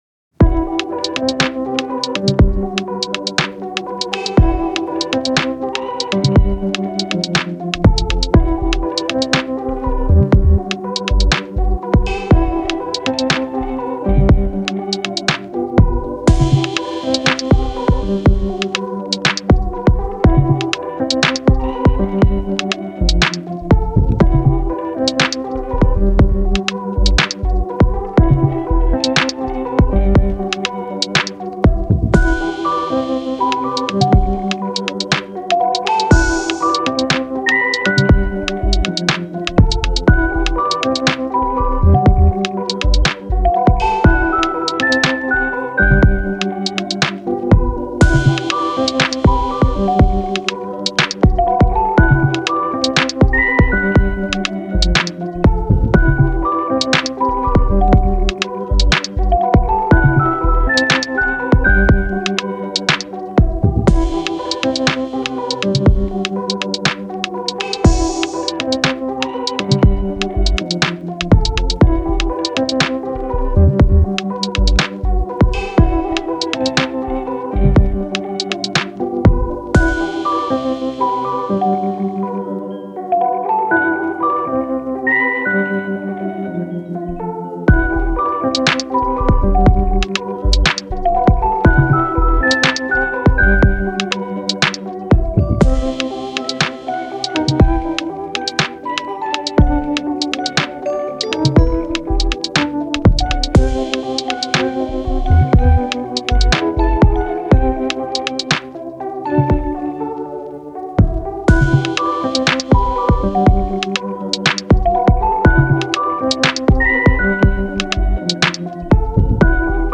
チル・穏やか
メロウ・切ない